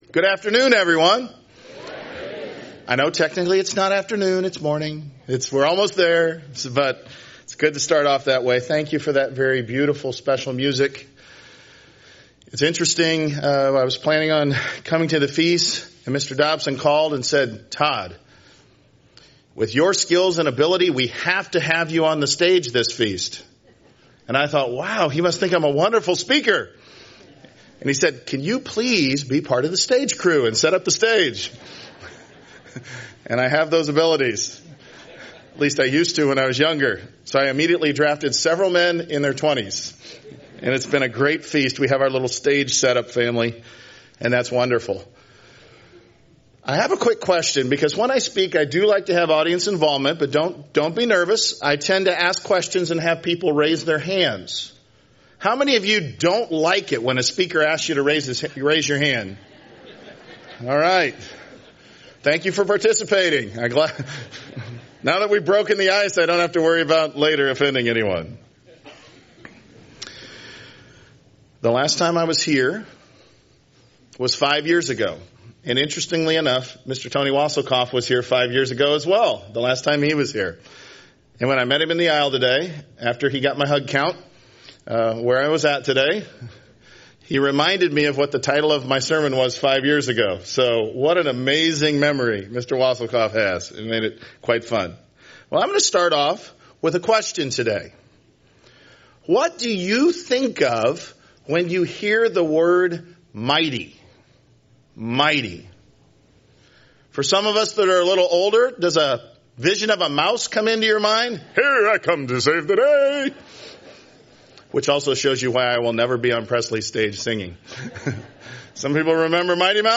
This Sermon starts with I Corinthians 1:26-31 - Not many mighty are called.